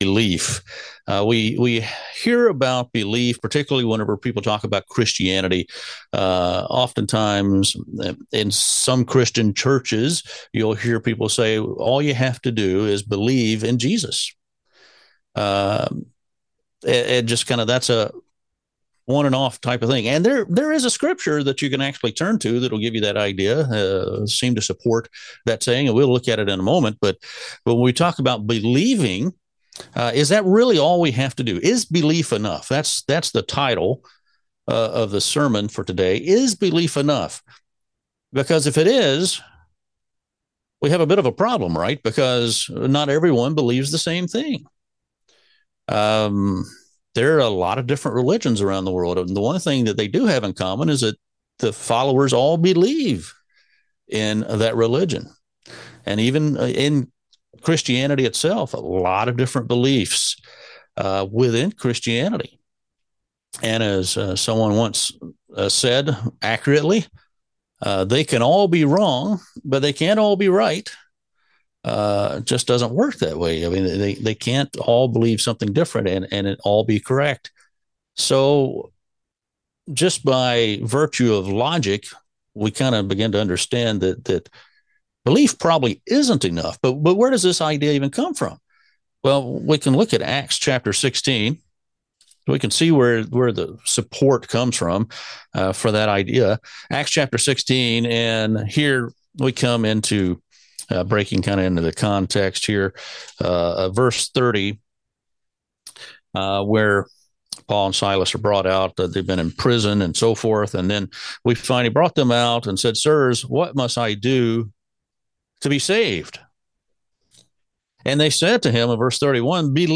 Given in Jacksonville, FL